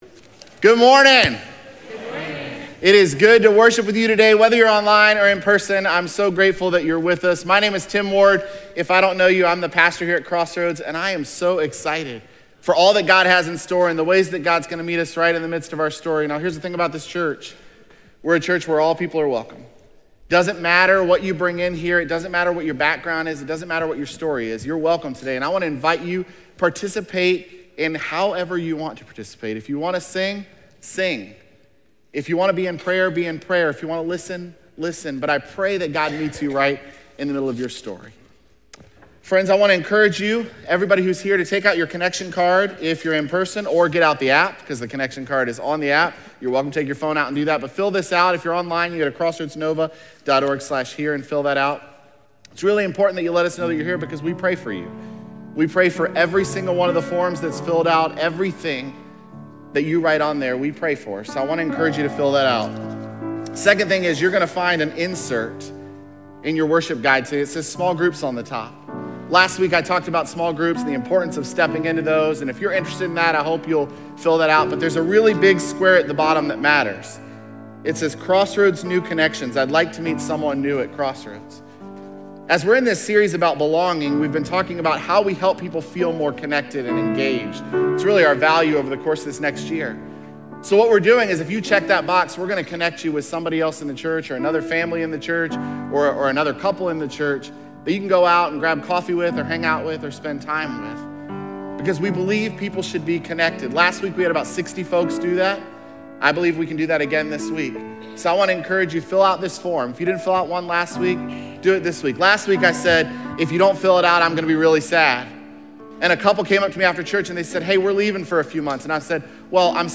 jan22sermon.mp3